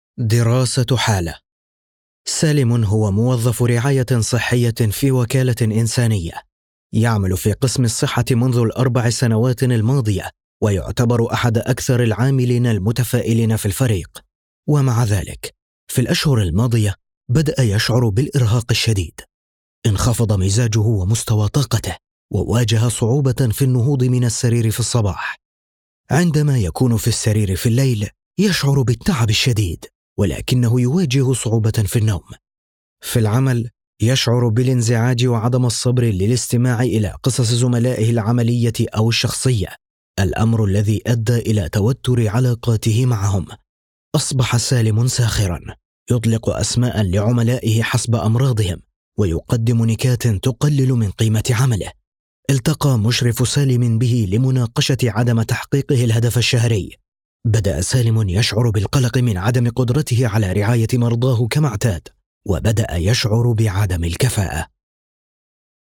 Male
A warm, confident Arabic voice with a clear, polished tone that instantly builds trust. The delivery is natural, steady, and engaging, balancing professionalism with approachability.